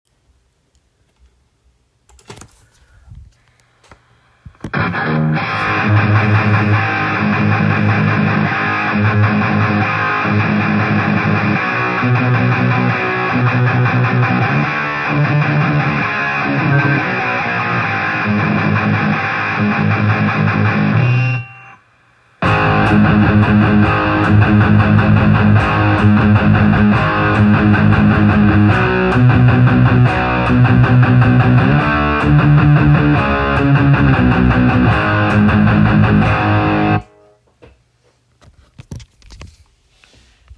Dünn und übersteuert nämlich. Ich habe versucht das festzuhalten und habe neben dem Lautsprecher mein iphone hingelegt und eine Sprachnotizaufnahme gemacht. Beim ersten Teil hört ihr die abgespielte Aufnahme über Cubase, beim zweiten Teil einfach den "Kempersound" so wie er live aus den Speakern rauskommt. Der Kemper ist über den Main Output L+R an ein Steinberg UR22 MKii Interface angeschlossen.